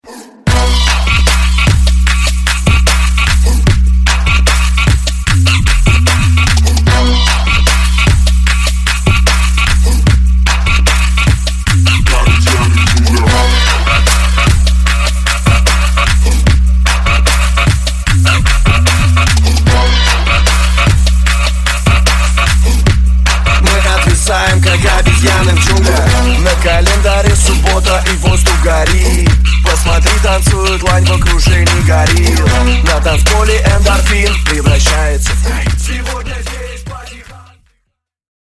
Громкие Рингтоны С Басами
Рингтоны Ремиксы » # Танцевальные Рингтоны